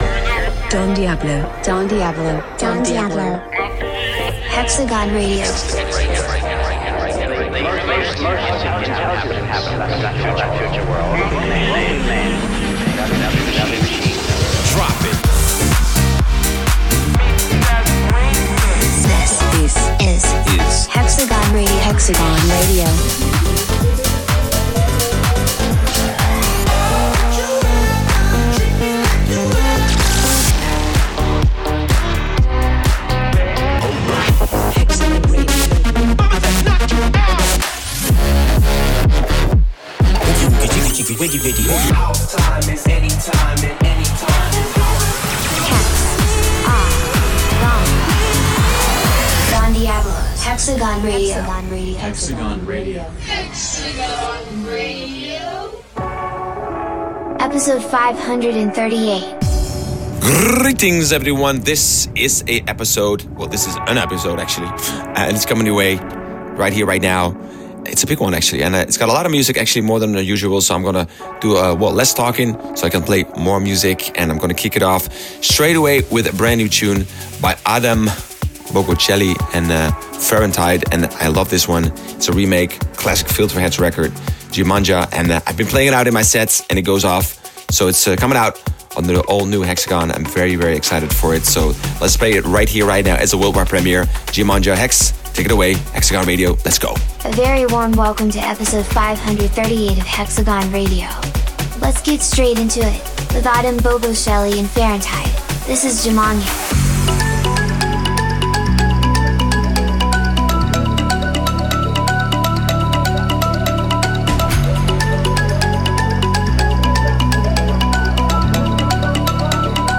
Also find other EDM Livesets, DJ Mixes and Radio